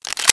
assets/nx/nzportable/nzp/sounds/weapons/mp40/magin.wav at 1ef7afbc15f2e025cfd30aafe1b7b647c5e3bb53